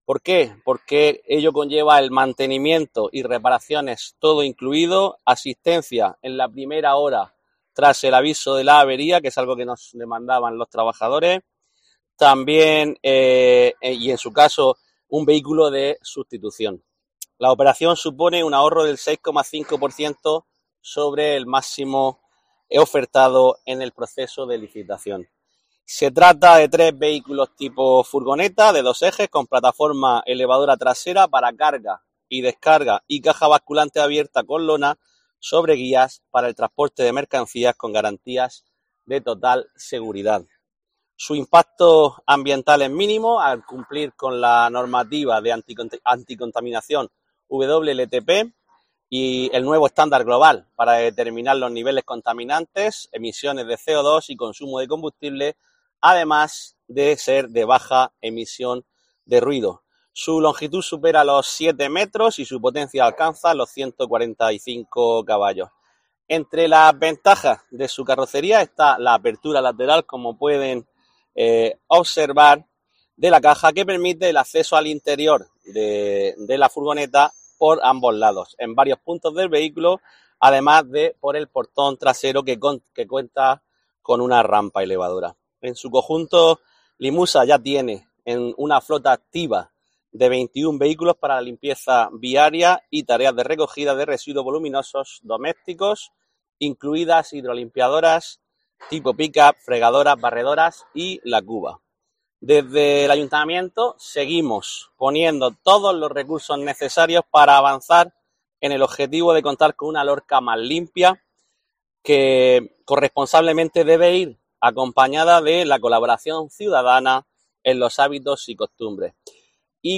Juan Miguel Bayona, concejal LIMUSA